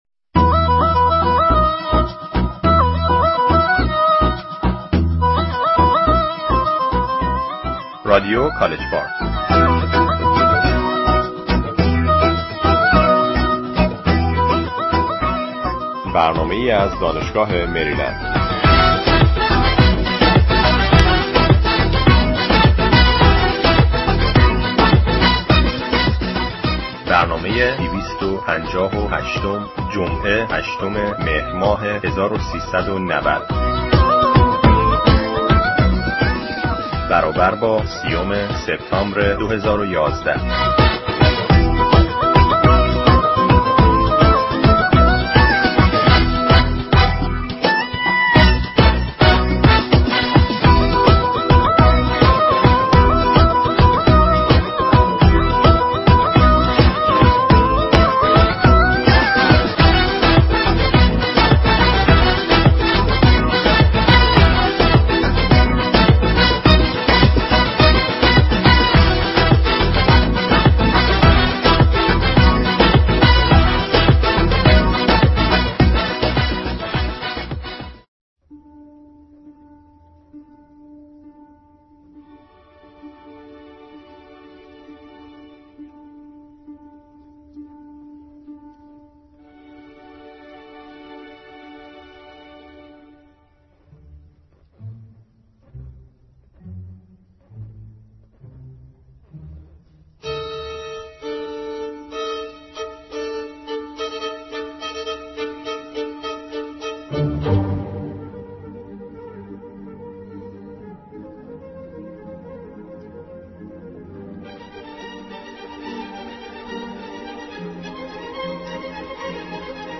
شعر طنزى از عمران صلاحى با صداى او